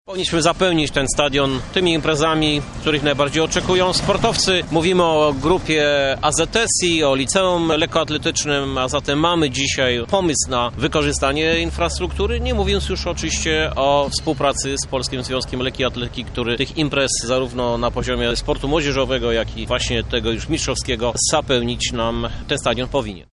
– Ta inwestycja związana jest nie tylko z rewitalizacją, ale posiada sens ekonomiczny – może być dobrym źródłem dochodu – tłumaczy Krzysztof Żuk, prezydent Lublina